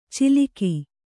♪ ciliki